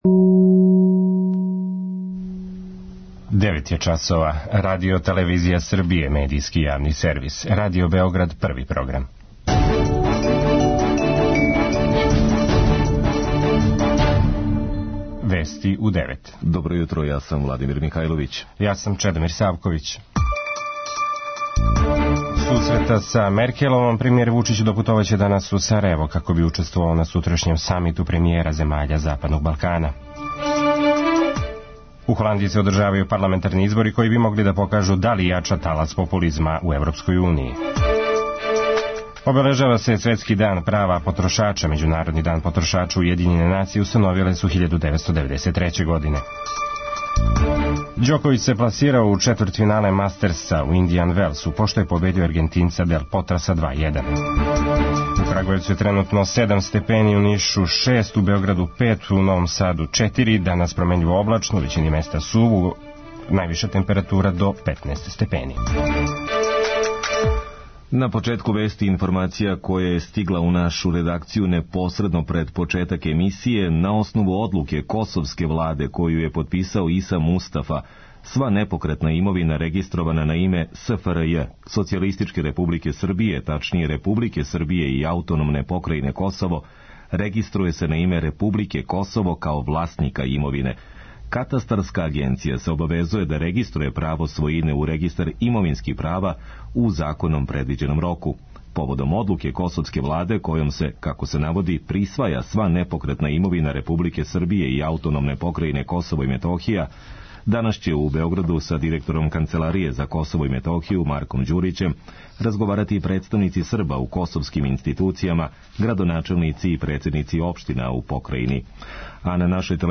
Највиша температура до 15 степени. преузми : 3.76 MB Вести у 9 Autor: разни аутори Преглед најважнијиx информација из земље из света.